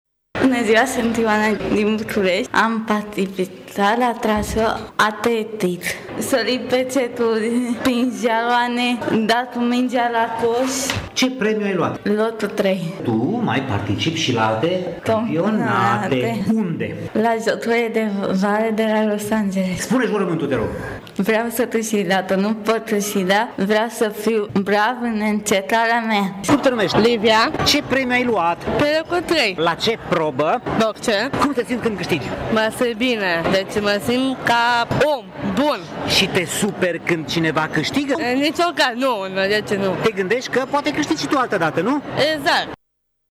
Sentimentul acesta era prezent astăzi peste tot, în sala de sport ”Anton Pongracz”, a Universității de Medicină și Farmacie Târgu-Mureș.
Am stat de vorbă cu două campioane, una dintre ele urmând să facă parte din lotul României, ce va participa, luna viitoare, la Jocurile mondiale ”Special olympics” de la Los angeles.